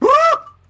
Scouto Scream
scouto-scream.mp3